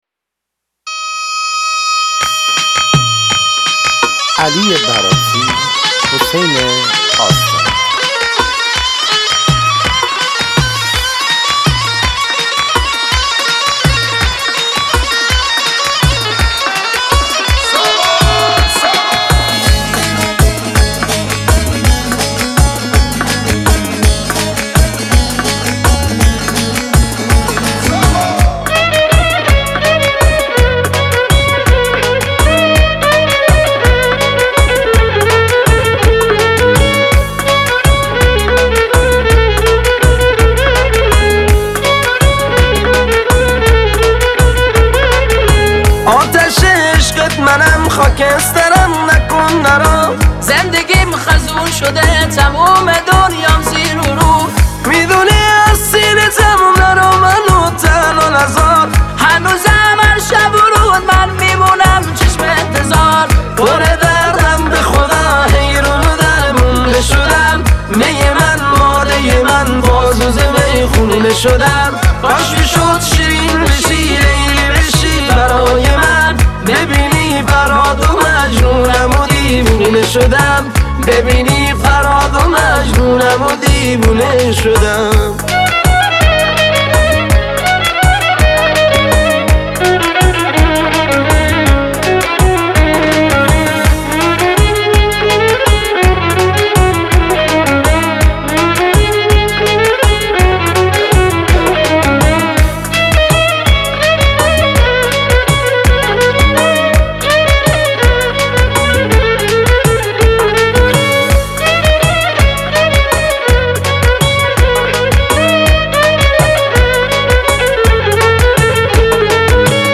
آهنگ محلی غمگین